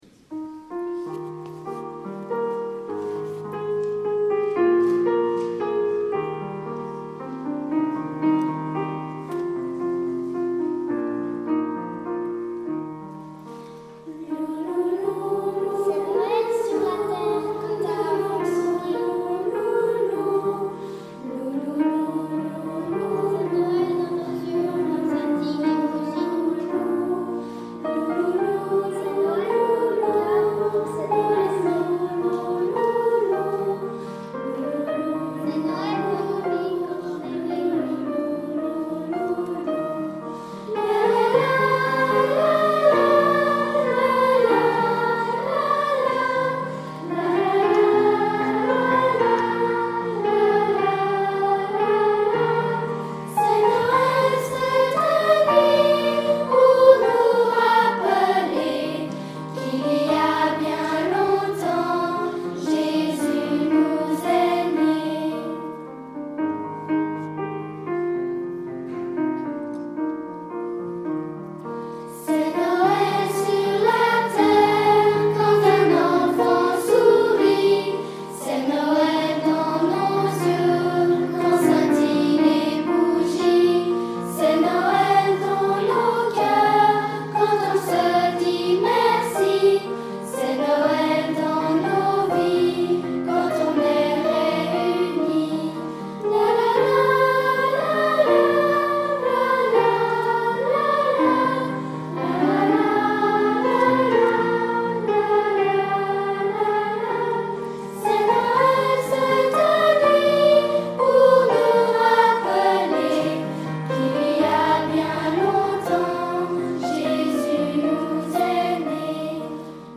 Concert de l'Avent (Arconciel) - Choeur d'enfants La Voix du Gibloux
CHOEUR DES GRANDS